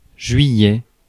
Ääntäminen
Ääntäminen France: IPA: [ʒɥi.jɛ] Haettu sana löytyi näillä lähdekielillä: ranska Käännös Ääninäyte Erisnimet 1.